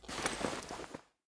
looting_3.ogg